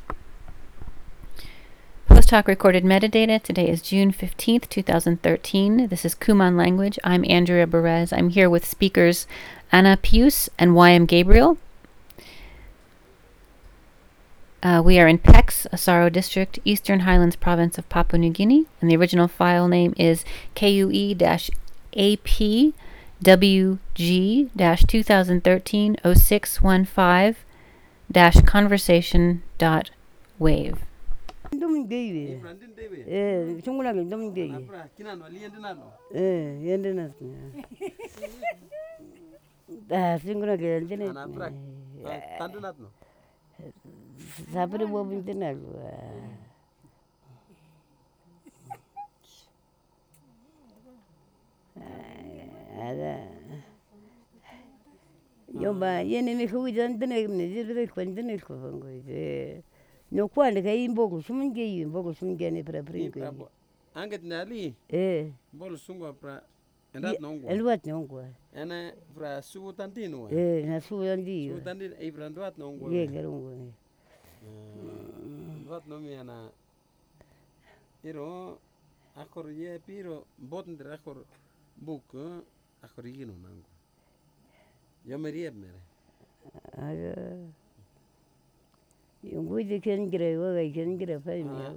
digital wav file recorded at 44.1 kHz/16 bit on Zoom H4n solid state recorder with Countryman e6 headset microphone
Pex Village, Asaro District, Eastern Highlands Province, Papua New Guinea